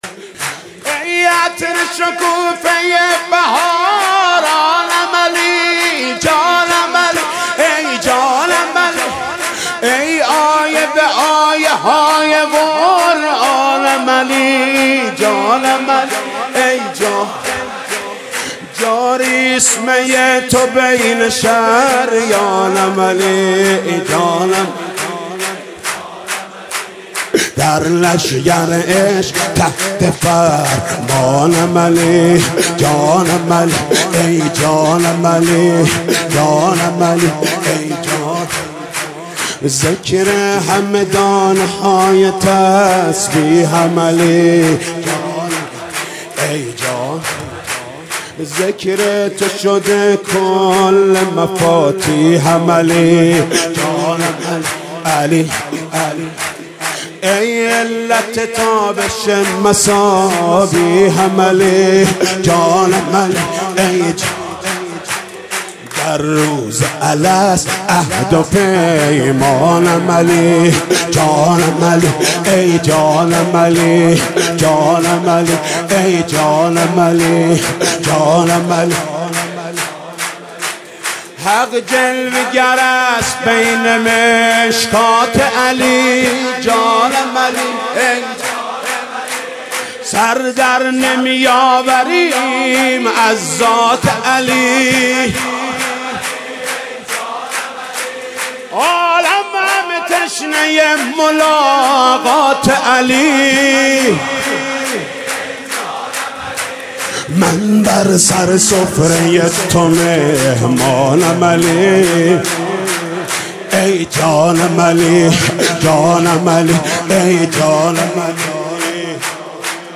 به مناسبت میلاد نورانی قرآن ناطق، امام علی(ع) مولودی خوانی محمود کریمی را می‌شنوید.